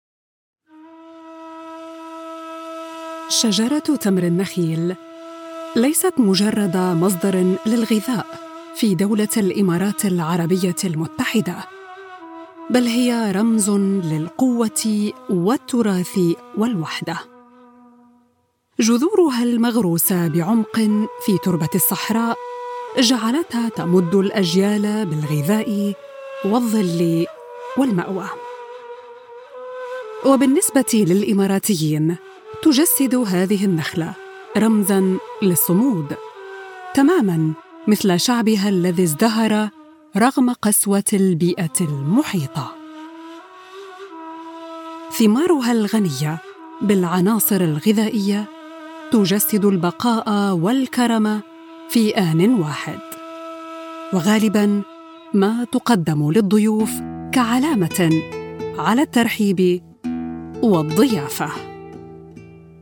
Arabic (Jordan), Middle Eastern, Female, 20s-40s